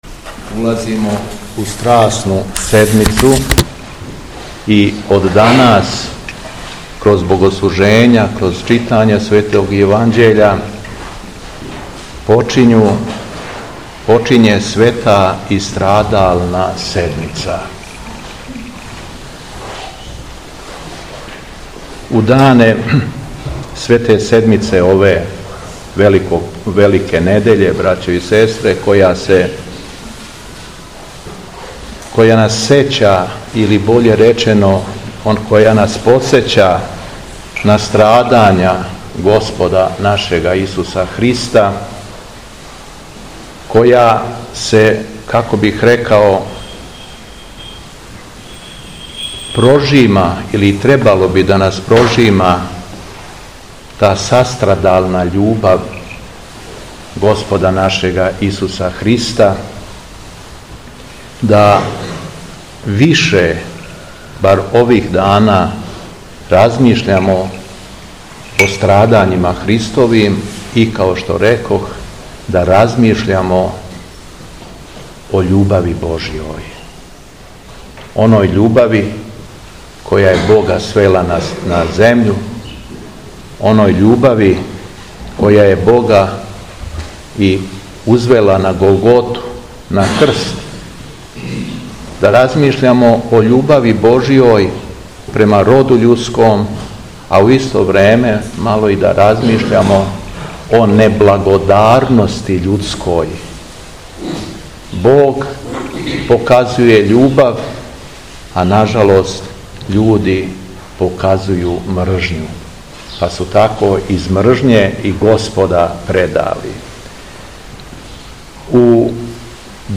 Беседа Његовог Високопреосвештенства Митрополита шумадијског г. Јована
Након прочитаног зачала Светог Јеванђеља по Матеју митрополит се обратио окупњеном верном народу: